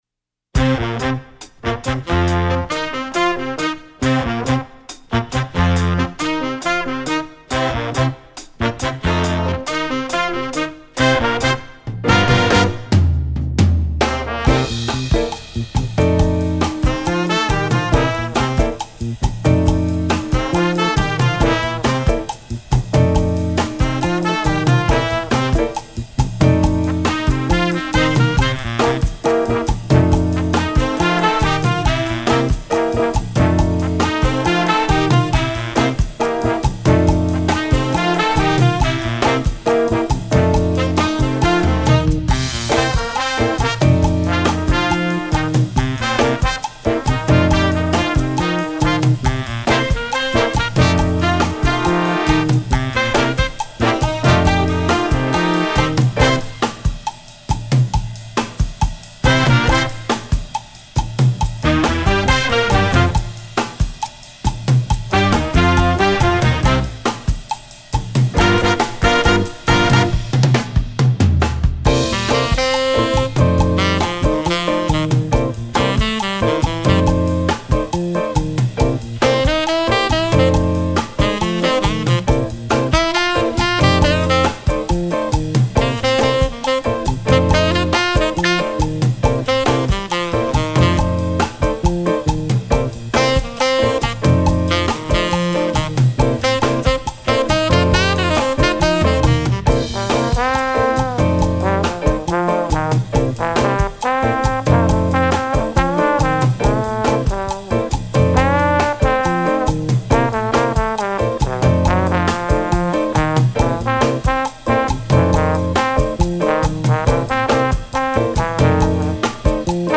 Instrumentation: jazz band